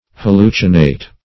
Hallucinate \Hal*lu"ci*nate\ (h[a^]l*l[=u]"s[i^]*n[=a]t), v. i.
hallucinate.mp3